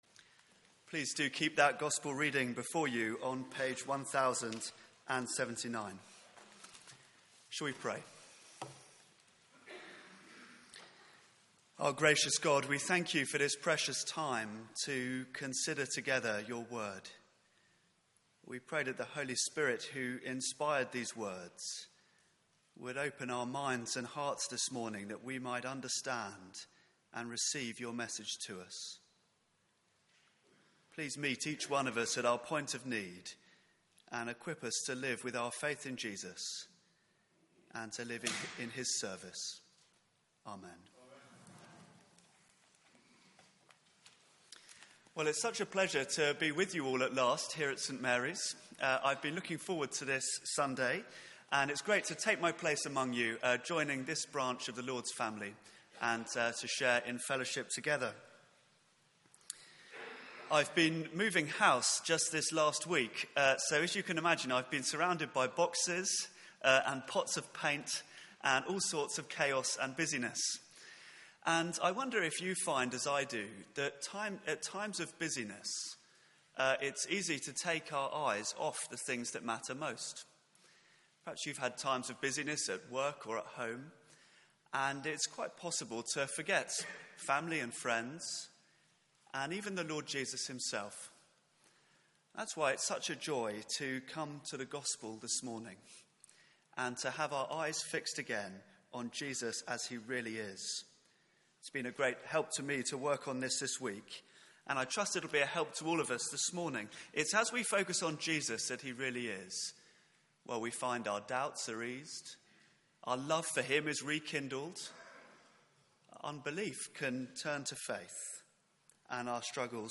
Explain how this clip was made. Media for 9:15am Service on Sun 01st May 2016 09:15 Speaker